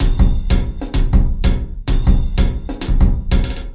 描述：奇数时间节拍120bpm
Tag: 回路 常规 时间 节奏 120BPM 节拍 敲击循环 量化 鼓环 有节奏